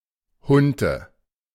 Hunte (German pronunciation: [ˈhʊntə]